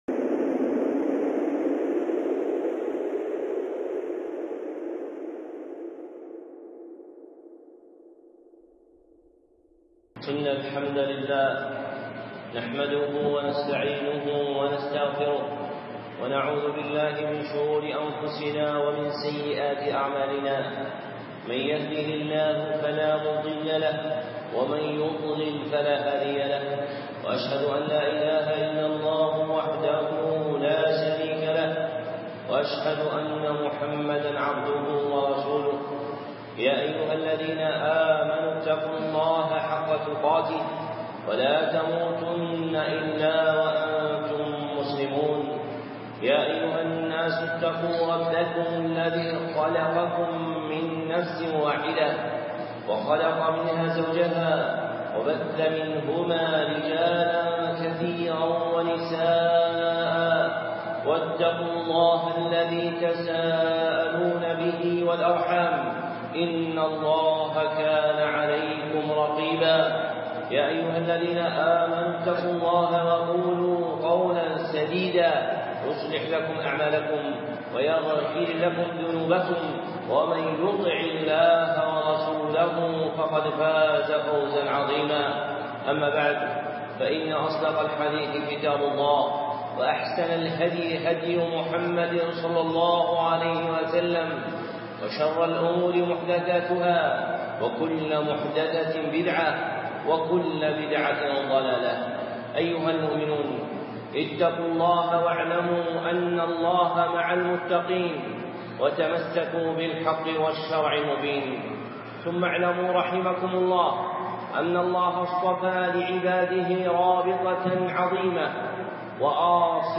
خطبة (أخوة المؤمنين) الشيخ صالح العصيمي